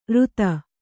♪ řta